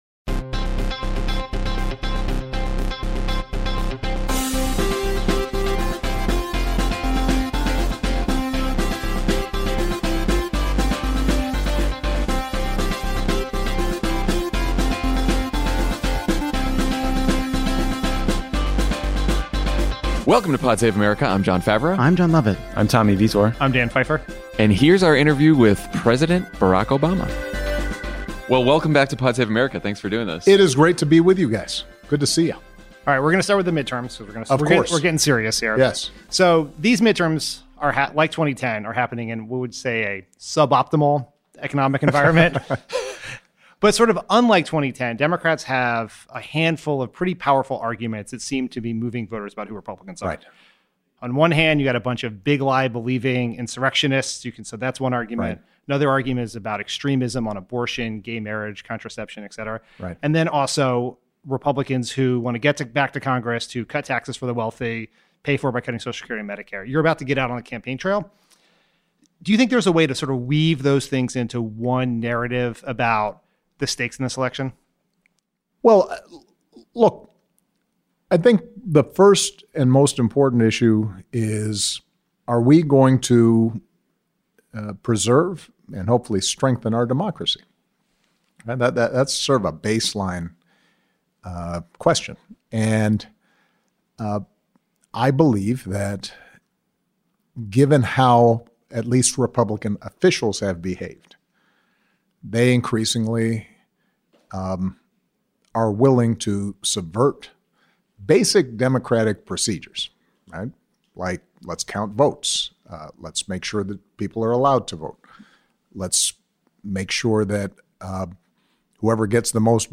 Barack Obama sits down for his first major interview of the midterm season to talk about the state of democracy here and abroad, the escalating situation in Iran and the ongoing war in Ukraine, and how Democrats can appeal to the broadest coalition of voters.